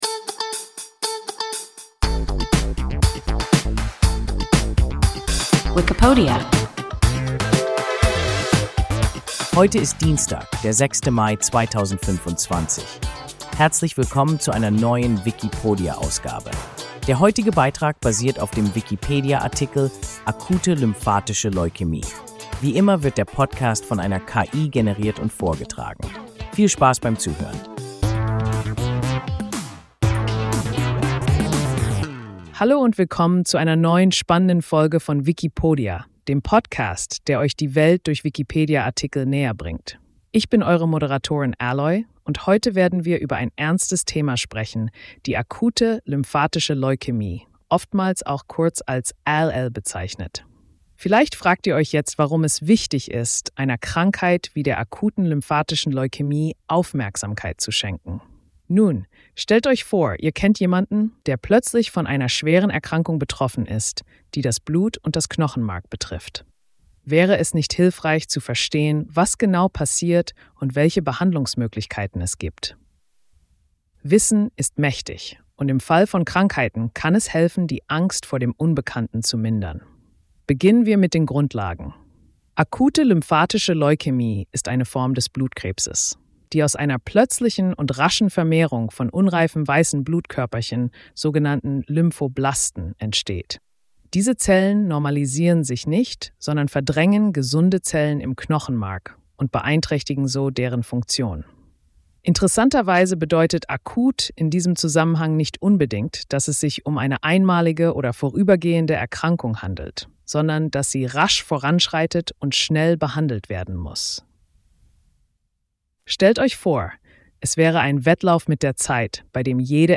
Akute lymphatische Leukämie – WIKIPODIA – ein KI Podcast